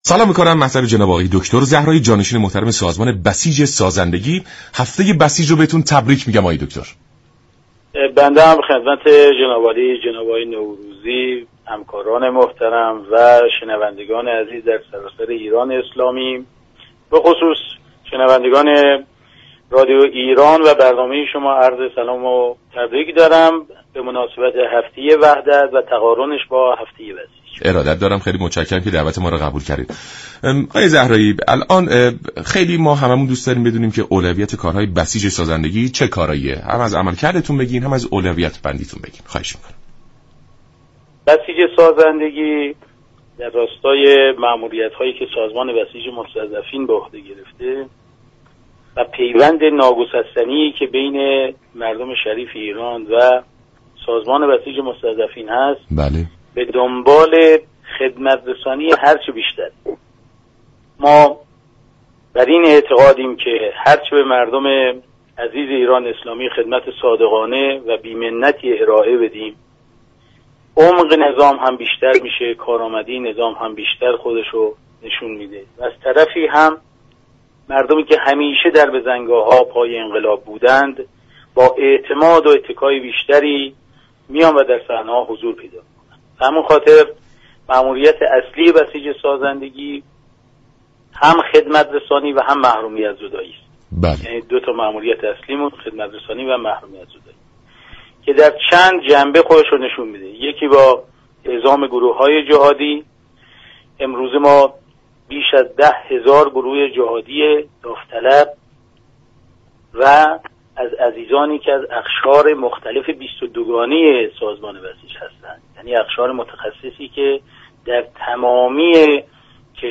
جانشین سازمان بسیج سازندگی در گفت و گو با رادیو ایران گفت